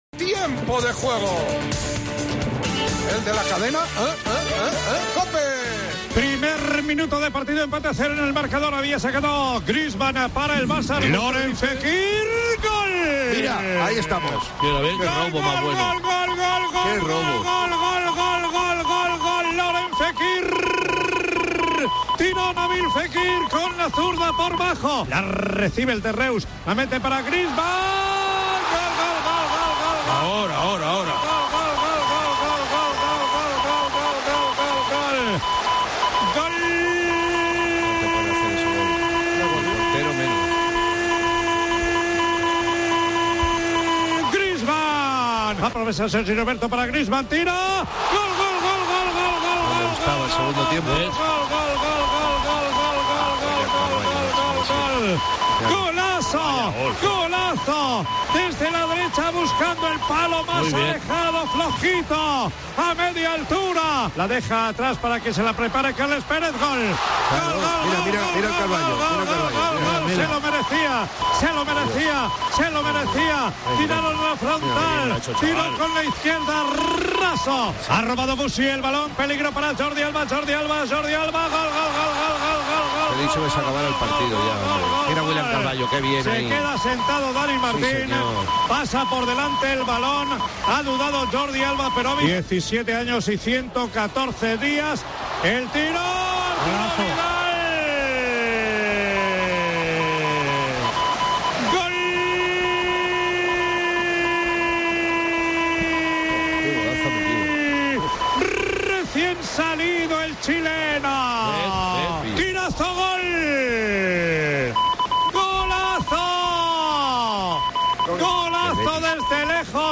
AUDIO: Escolta els gols del Barça 5-Betis 2 i el debut d'Ansu Fati amb narració